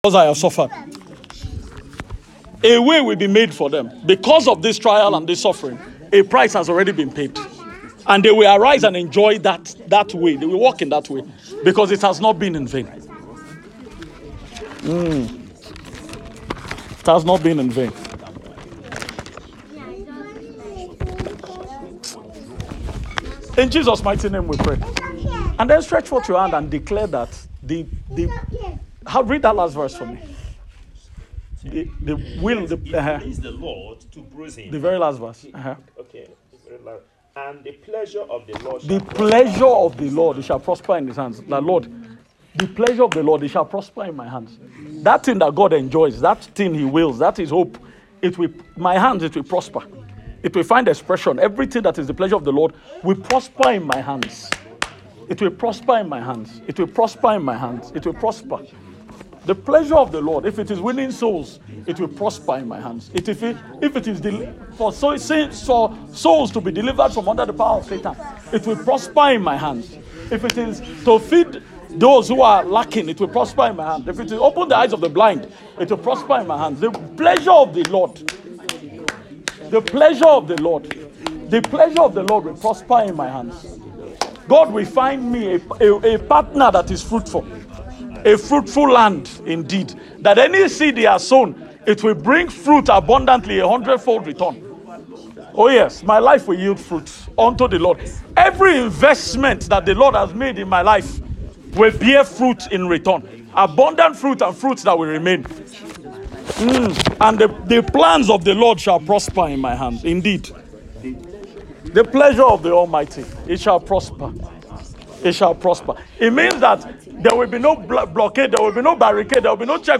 GARAM VILLAGE Discipleship 18/02/25